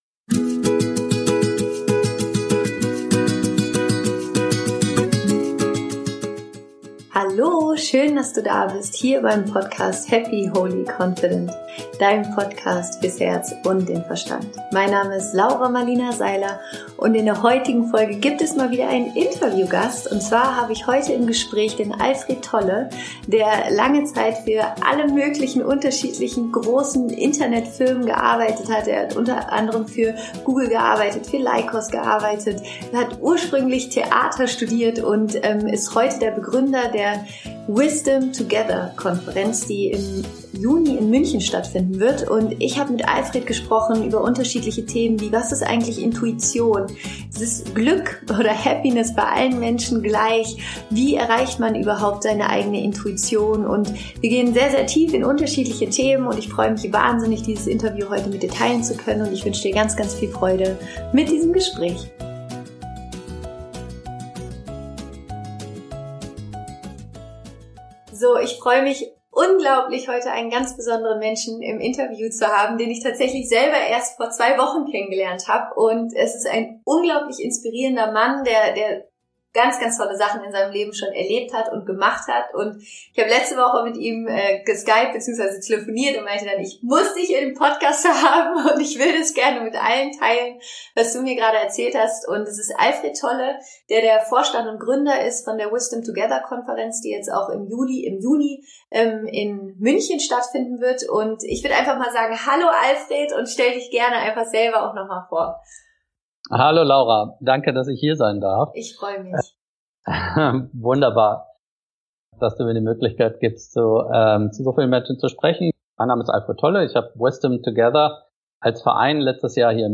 Interview Special